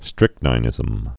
(strĭknī-nĭzəm, -nĭ-, -nē-)